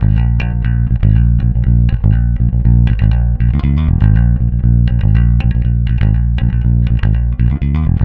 -JP THROB A#.wav